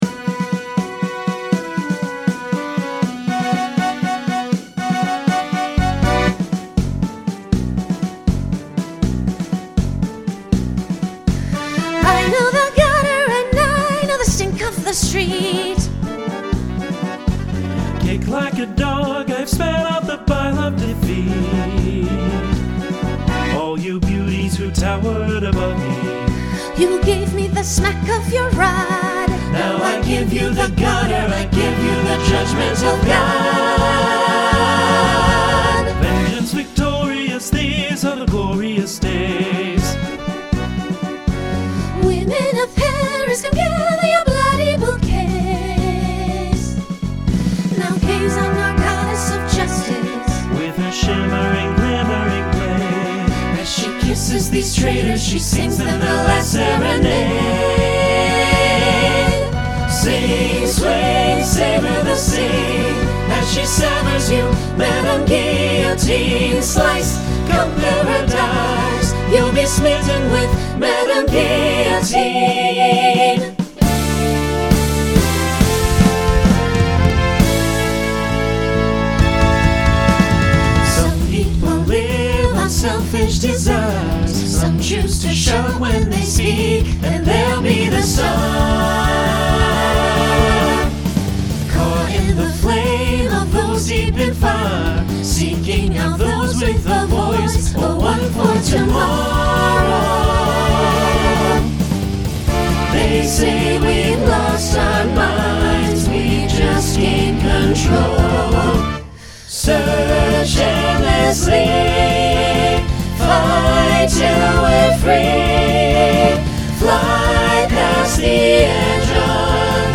Genre Broadway/Film , Rock Instrumental combo
Voicing SATB